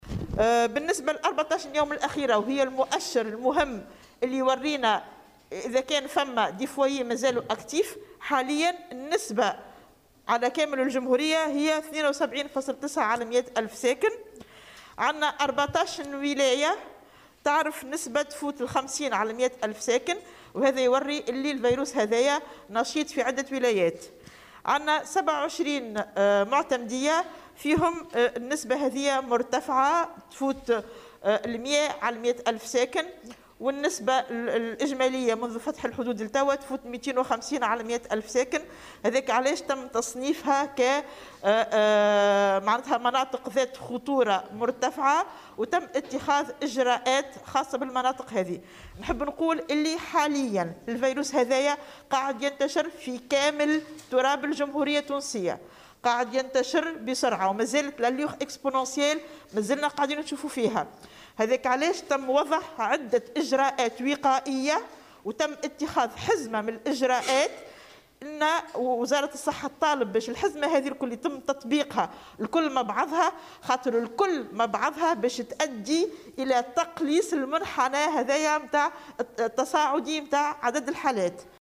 وأضافت خلال ندوة صحفية عقدتها وزارة الصحة اليوم أنه تم خلال الـ14 يوما الأخيرة، تسجيل نسبة 72.9 إصابة لكل 100 ألف ساكن ( أكثر من 50 بالمائة لكل 100 الف ساكن في 14 ولاية).وأوضحت أيضا أن 27 معتمدية سجّلت نسبة مرتفعة للإصابات بمعدّل فاق 100 لكل 100 ألف ساكن، الأمر الذي عجّل بتصنيفها كمناطق ذات خطورة عاليا واتخاذ حزمة من الاجراءات الخاصة بها.